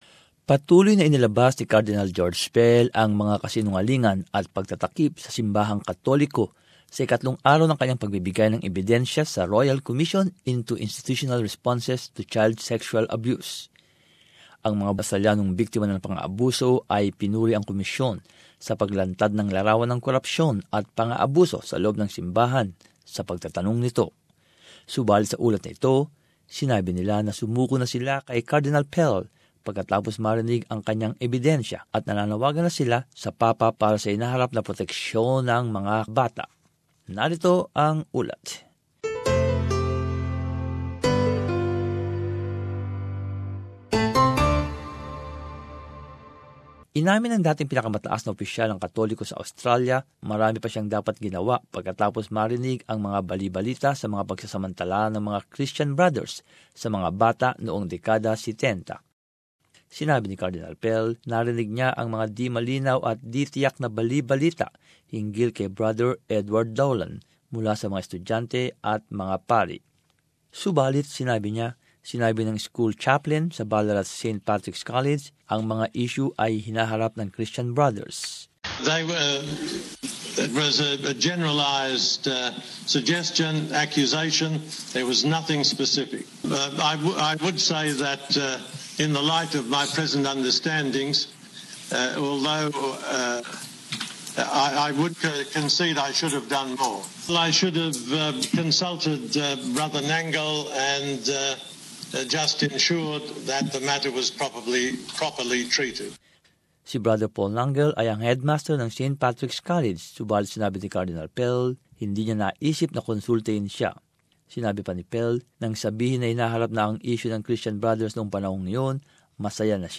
But in this report, they say they have given up on Cardinal Pell after hearing his evidence and are calling on the Pope for the future protection of children.